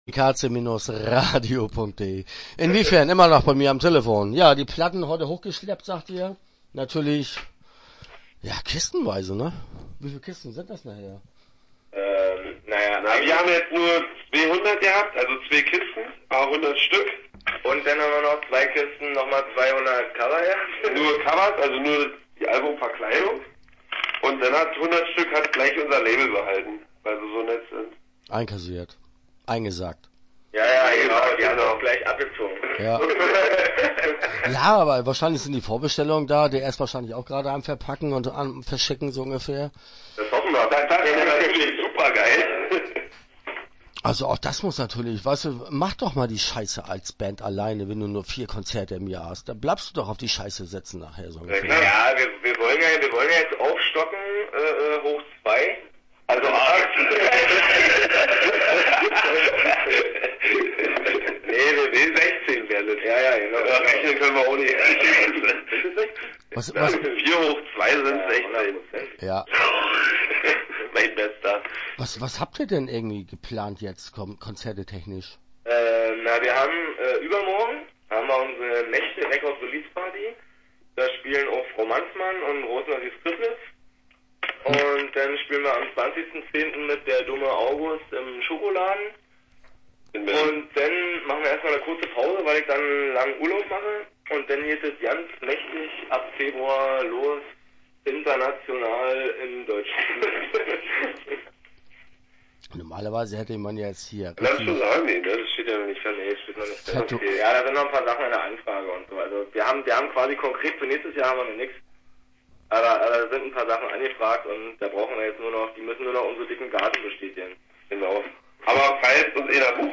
Start » Interviews » Inwiefern